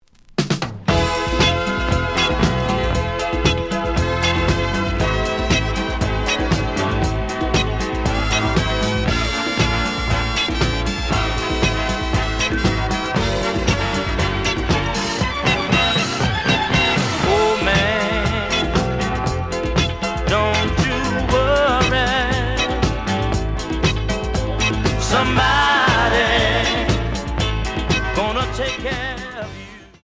The audio sample is recorded from the actual item.
●Genre: Disco
Slight edge warp.